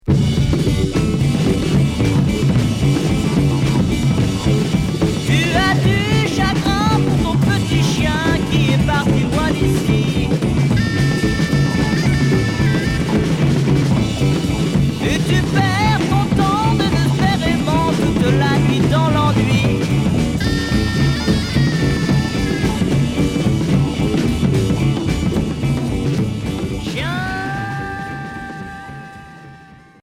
Pop psychédélique Unique 45t retour à l'accueil